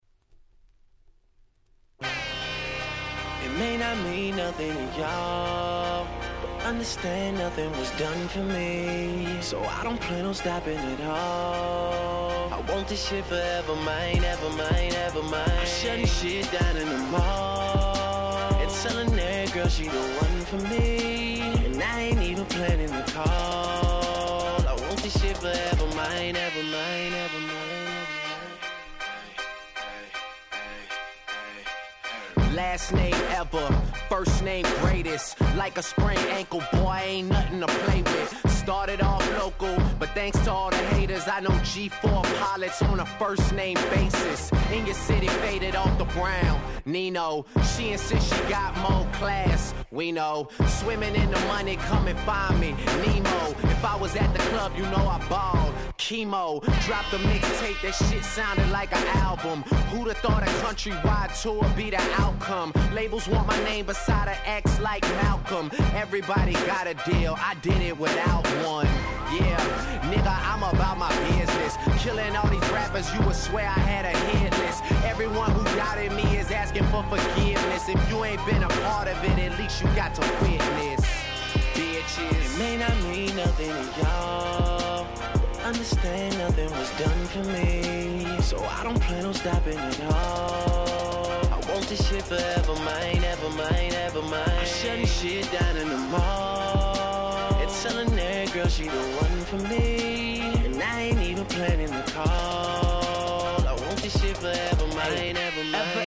09' Super Hit Hip Hop !!